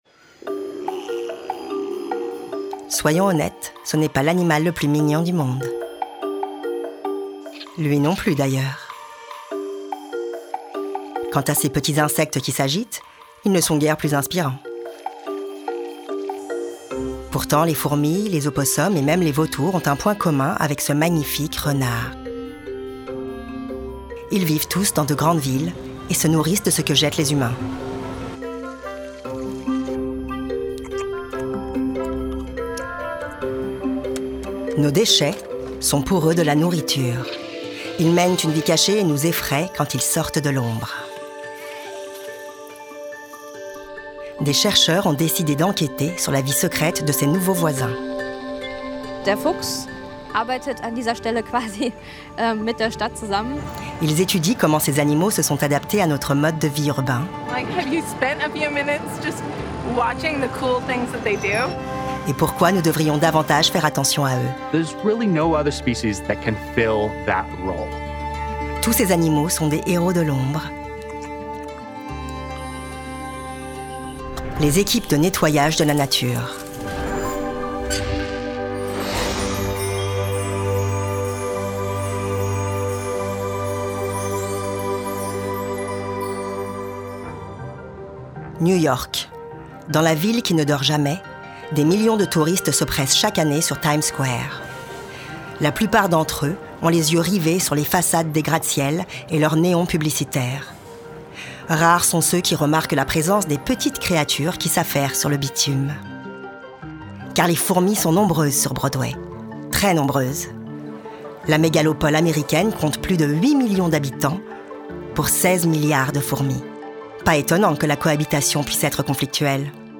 Démo Narration
Narratrice
30 - 65 ans - Soprano